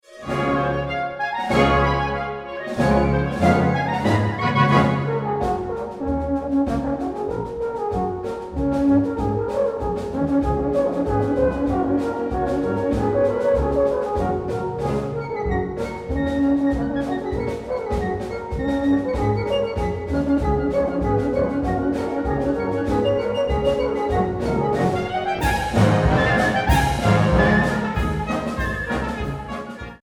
☰ Swiss Folk Music
for Alphorn and wind band
Description:Band music; folk music; music for alphorn
Instrumentation:Wind band, alphorn